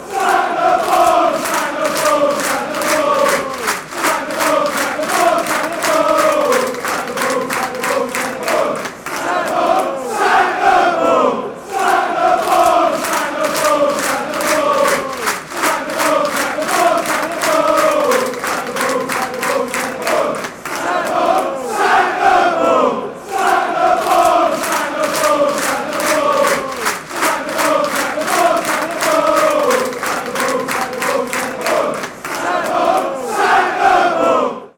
cantos de la hinchada de Upton Park